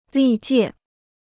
历届 (lì jiè): all previous (meetings, sessions etc).